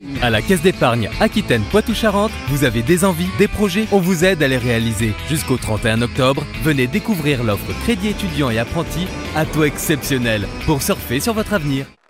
广告动画-激情活力